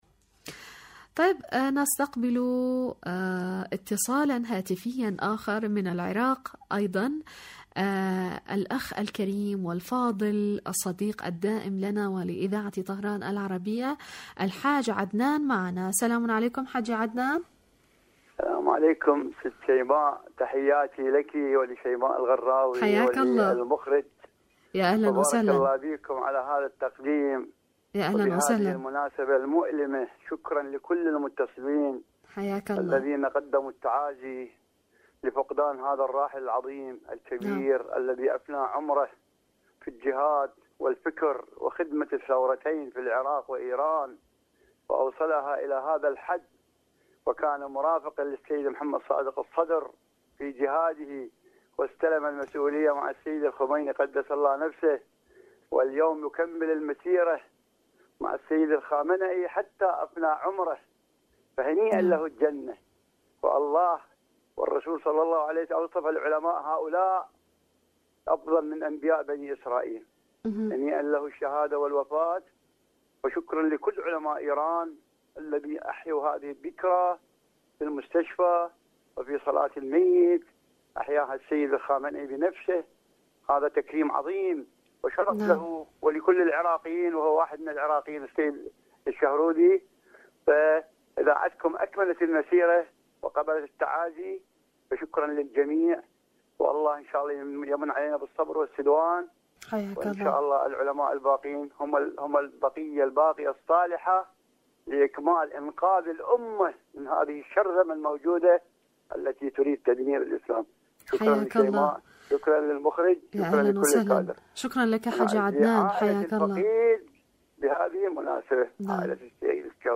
برنامج : المنتدى الإذاعي / مشاركة هاتفية